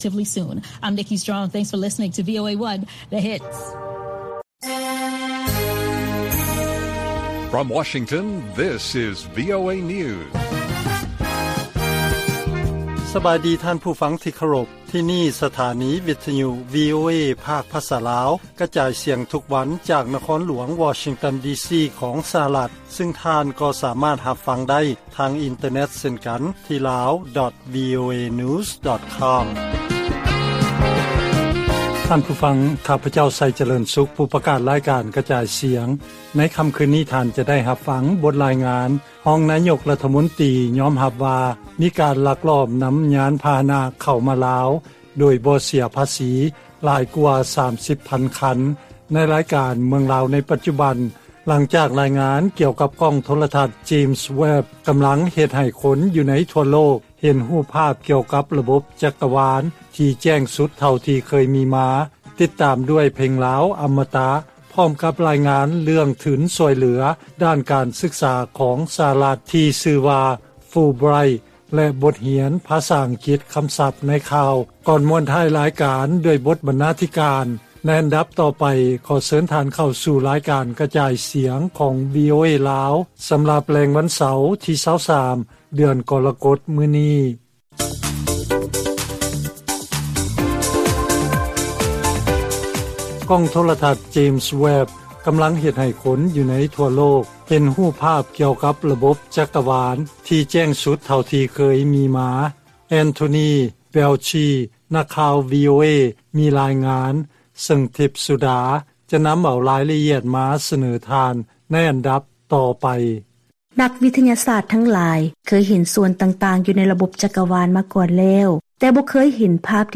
ລາຍການກະຈາຍສຽງຂອງວີໂອເອລາວ: ການສຶກສາໃນສະຫະລັດ ເຮັດໃຫ້ໄດ້ທັງຄວາມຮູ້ ແລະພັດທະນາຕົນເອງຮອບດ້ານ